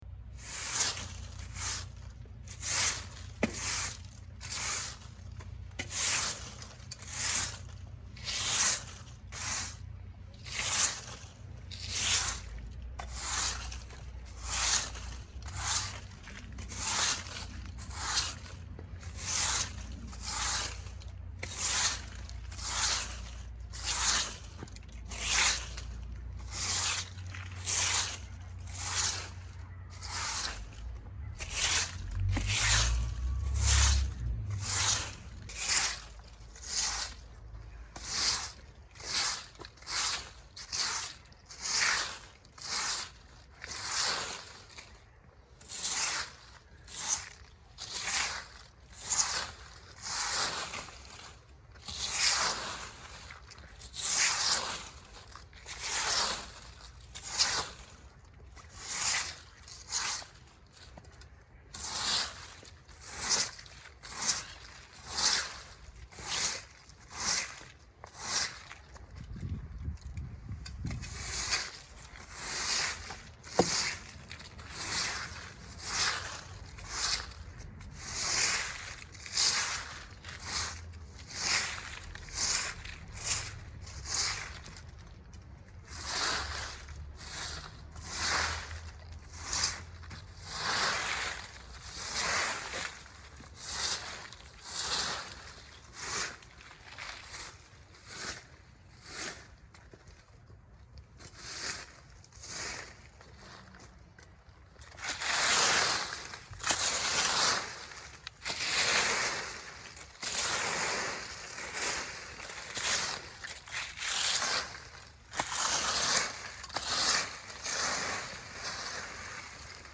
Звуки метлы
Звук метлы, скользящей по тротуару, и шелест опавших листьев: